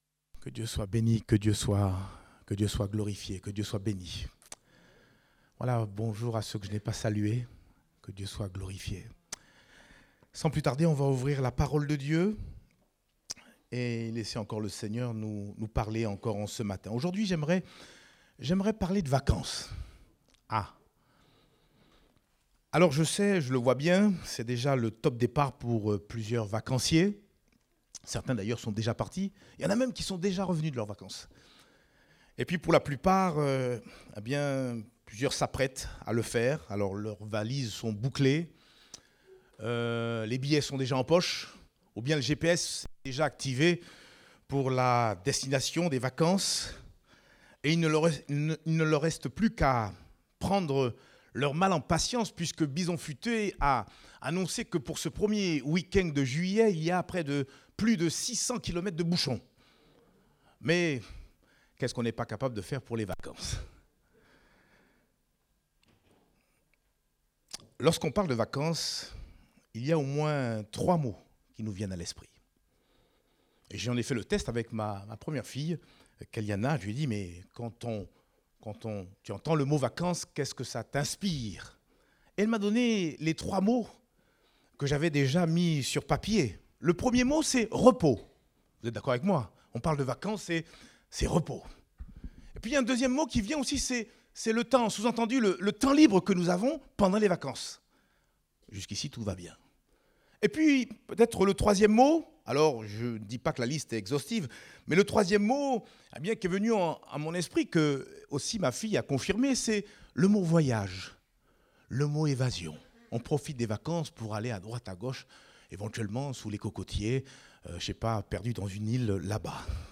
Date : 26 mai 2024 (Culte Dominical)